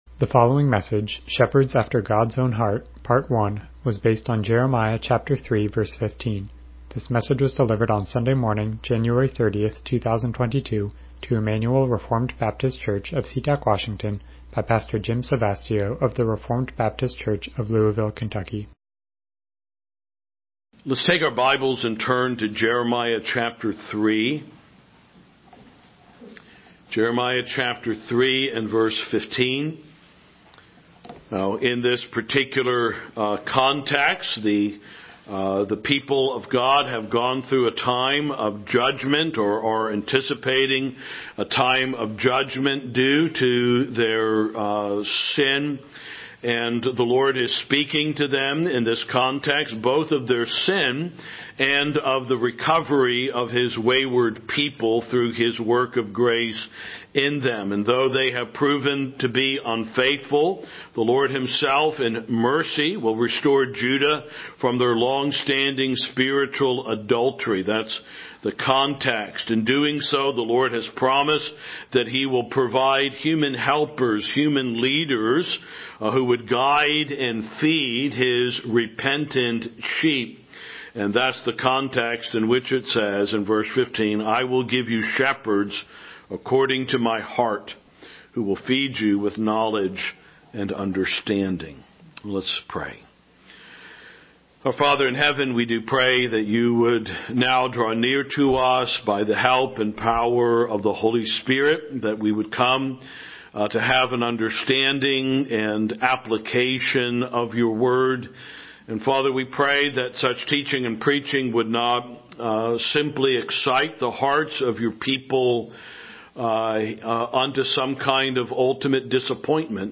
Passage: Jeremiah 3:15 Service Type: Morning Worship « LBCF